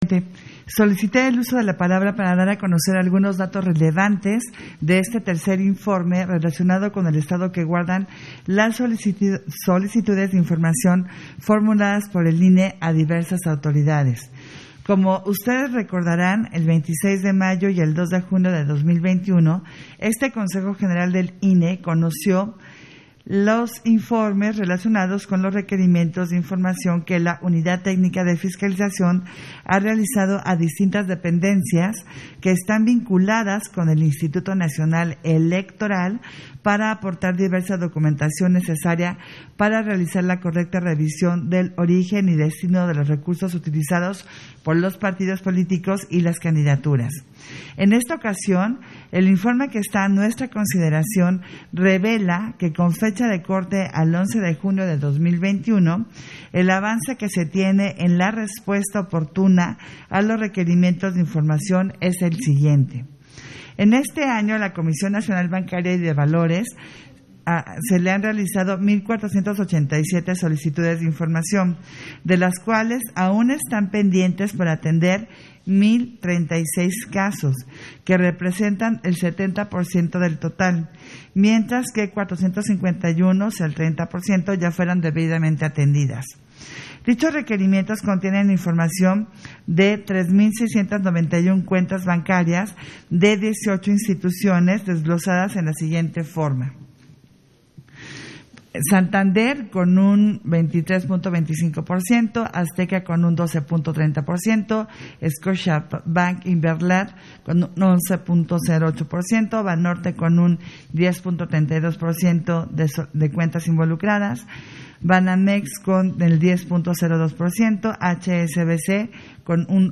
Intervención de Adriana Favela en Sesión Extraordinaria, Relativo al informe del estado de solicitudes de información a las Autoridades Fiscales y Financieras